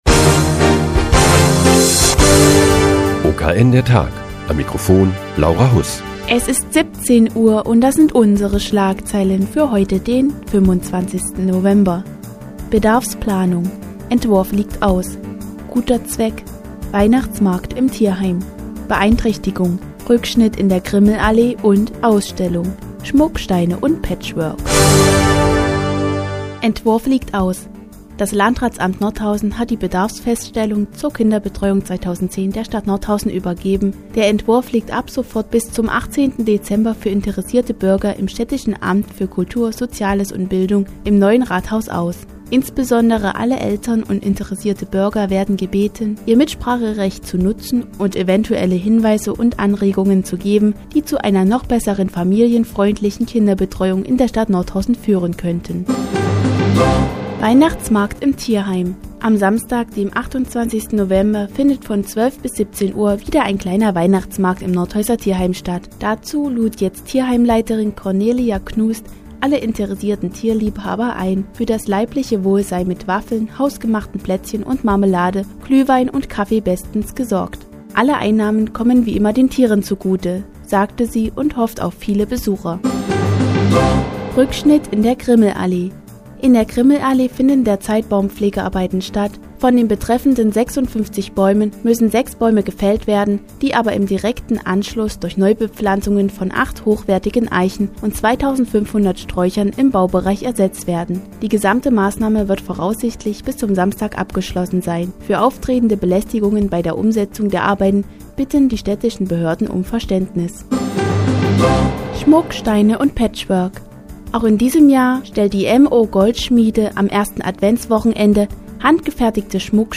Die tägliche Nachrichtensendung des OKN ist nun auch in der nnz zu hören. Heute geht es um einen Weihnachtsmarkt im Tierheim und eine "schmucke" Ausstellung im Museum Tabakspeicher.